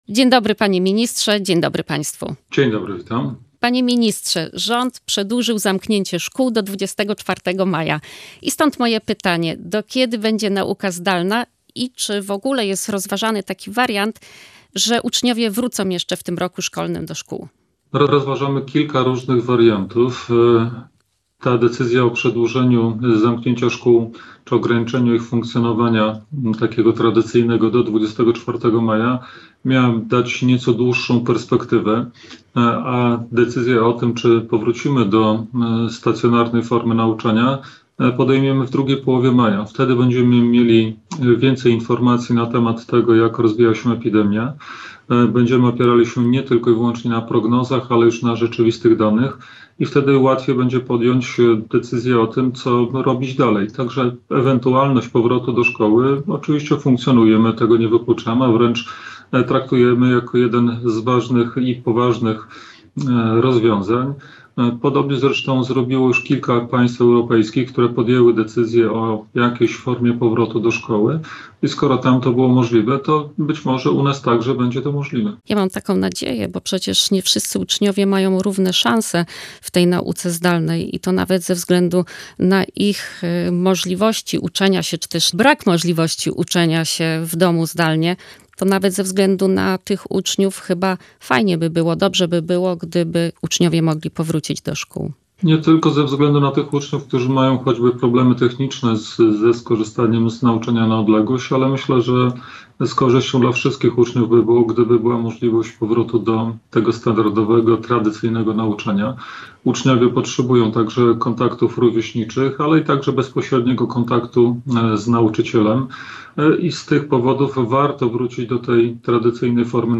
Między innymi na te pytania odpowiedział Gość Dnia Radia Gdańsk – minister edukacji narodowej Dariusz Piontkowski.